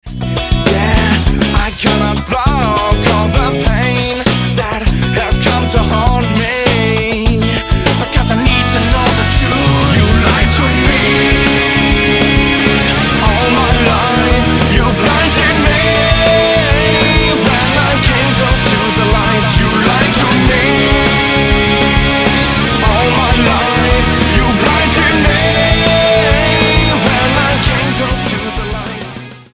Gutsy rock unit
Style: Rock